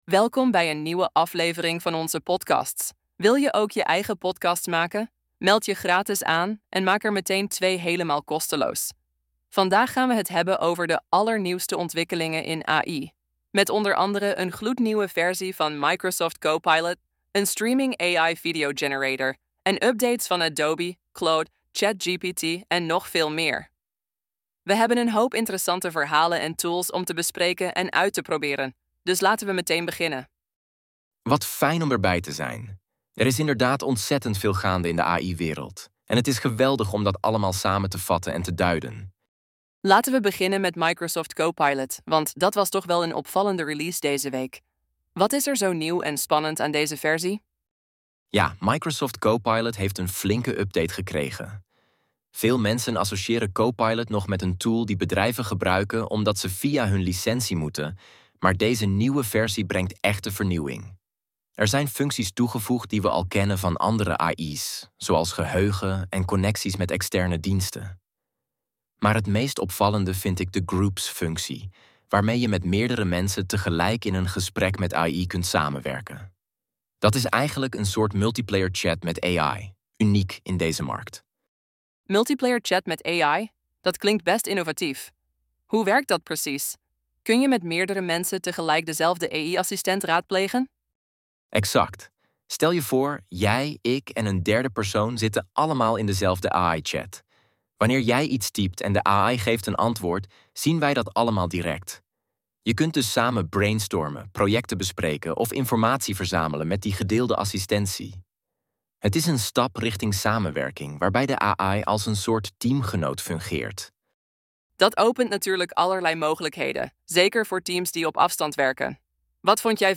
Podcast gegenereerd van geüploade script: 𝗔𝗜 𝗯𝗲𝘄𝗲𝗲𝗴𝘁 𝘀𝗻𝗲𝗹𝗹𝗲𝗿 𝗱𝗮𝗻 𝗼𝗼𝗶𝘁 — 𝗲𝗻 𝘄𝗶𝗷 𝗽𝗿𝗮𝘁𝗲𝗻 𝗷𝗲 𝗯𝗶𝗷!